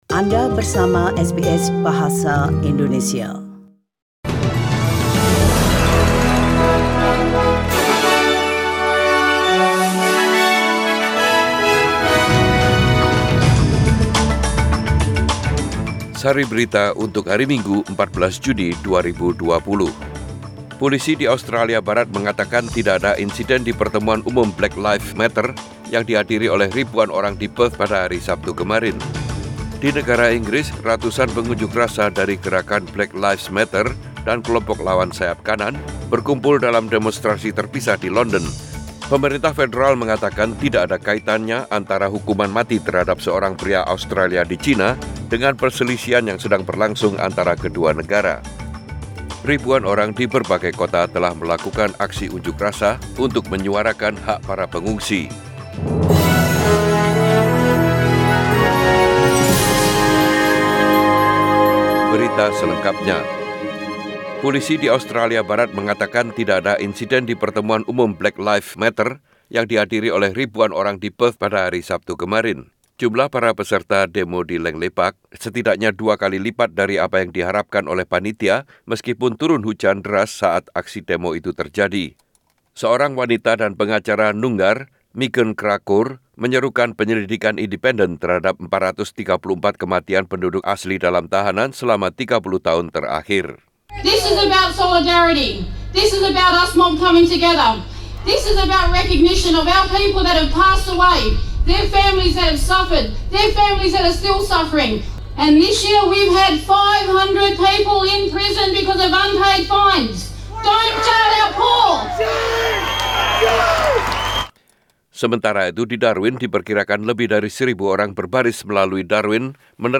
SBS Radio News in Bahasa Indonesia - 14 June 2020
Warta Berita Radio SBS Program Bahasa Indonesia Source: SBS